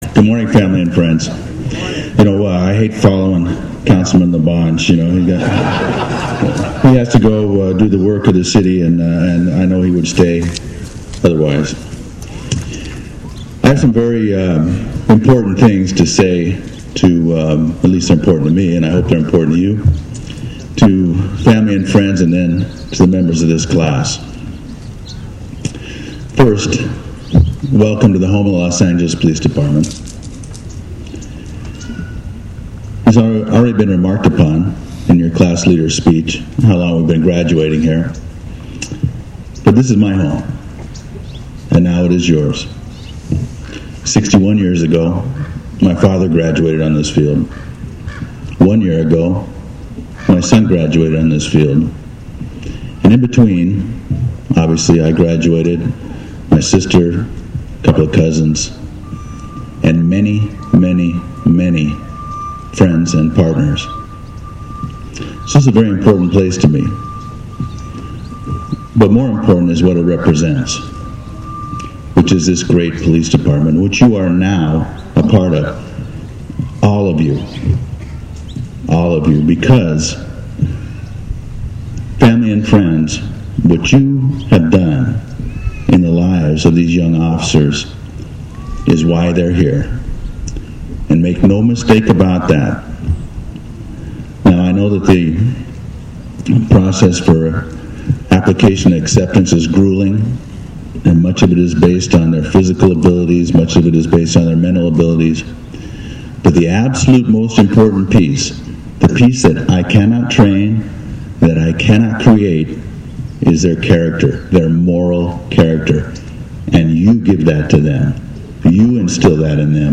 He always delivers his speech to the officers straight from the heart.
Chief Beck ended his speech with welcoming and congratulating the new officers to the Los Angeles Police Department.